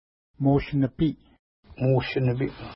Pronunciation: mu:ʃ-nəpi:
Pronunciation